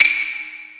soft-hitwhistle2.mp3